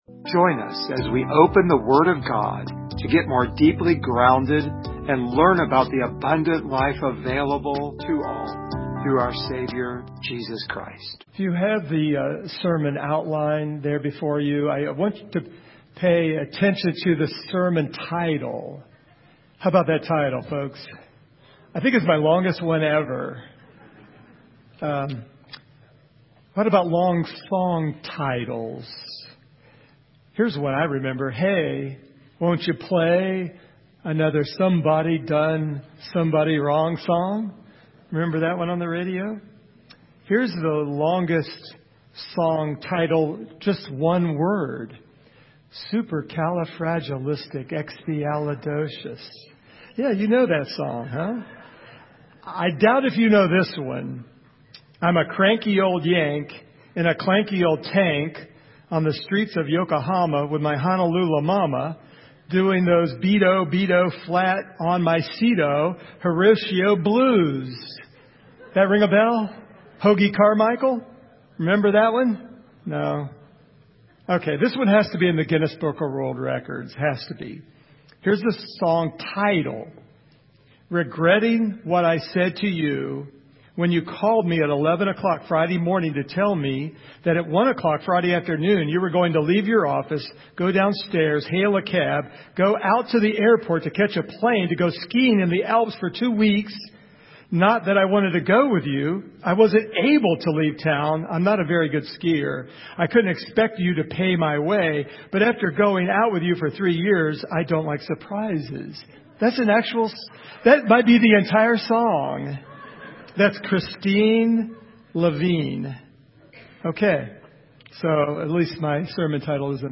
Service Type: Sunday Morning
Topics: Christian Life , Pruning share this sermon « What Shall I Do With Jesus?